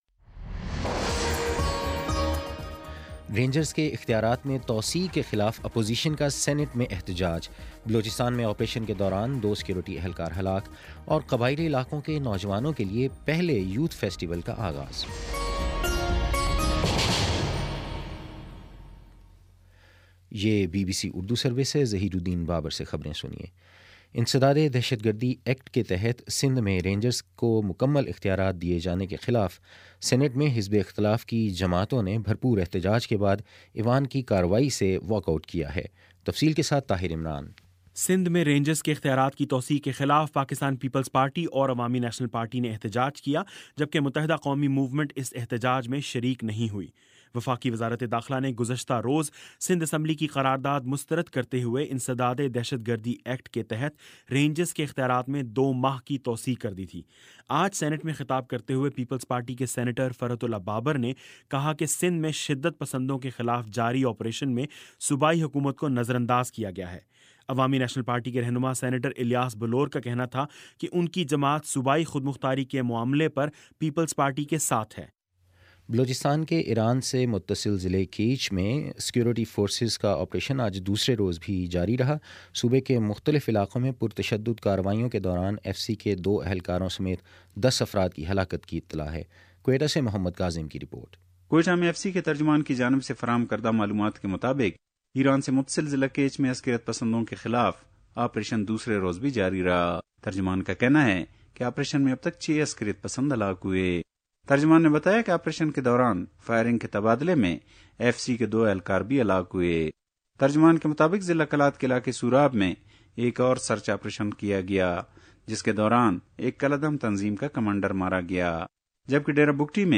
دسمبر 23 : شام پانچ بجے کا نیوز بُلیٹن